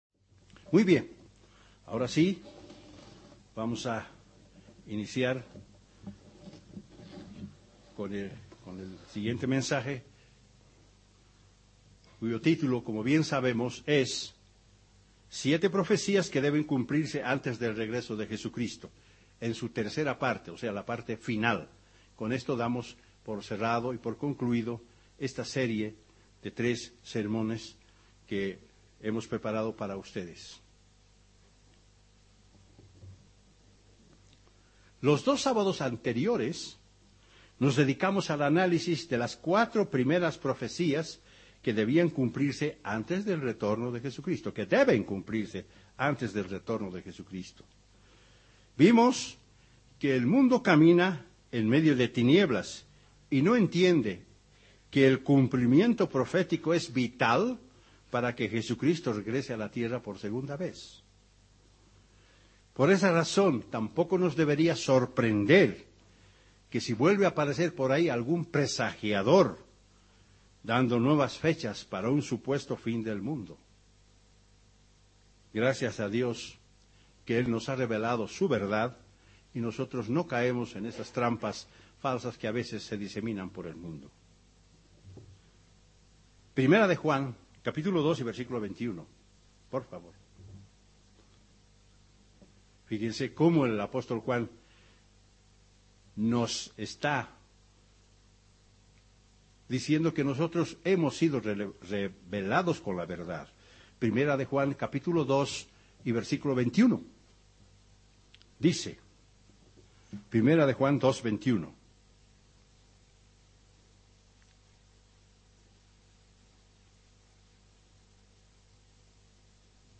¿Cuál es el escenario y eventos que deben cumplirse para el retorno de Jesucristo? En este sermón analizaremos las últimas 3 profecías que deben suceder.